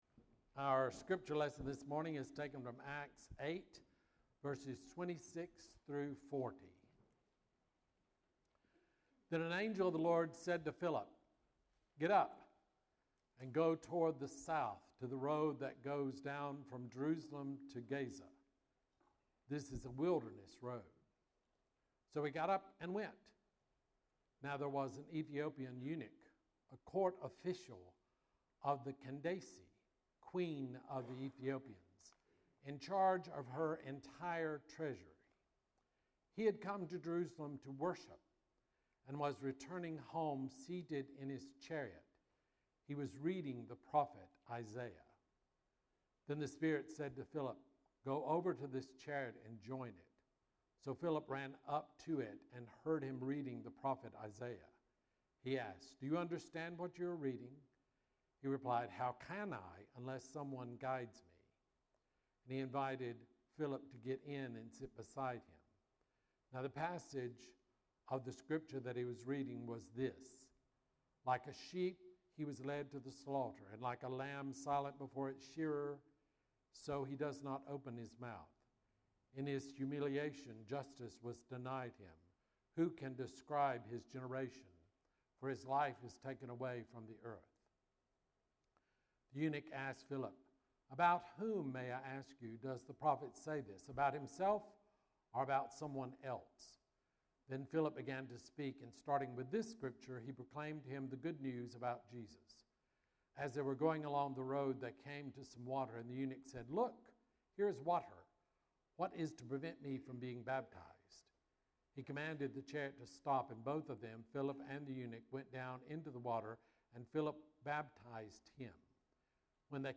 Bible Text: Acts 8:26-40 | Preacher